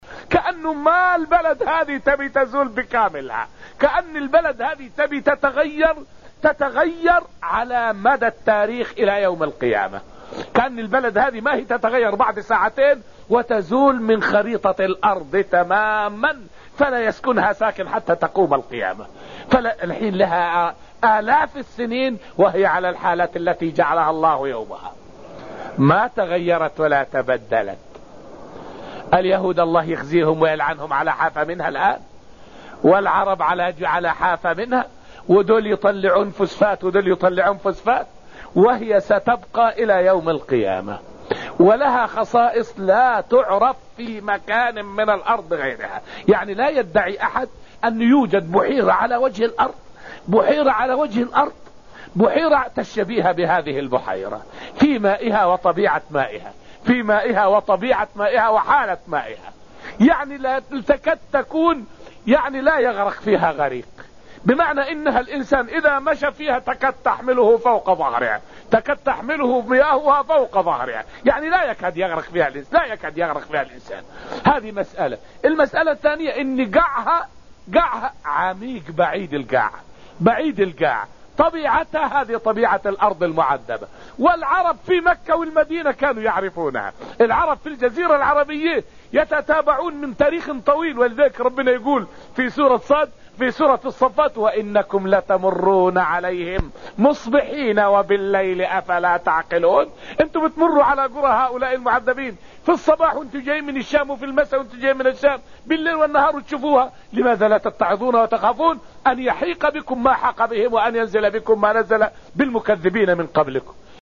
فائدة من الدرس الرابع من دروس تفسير سورة الذاريات والتي ألقيت في المسجد النبوي الشريف حول حال الأرض التي عُذب فيها قوم لوط.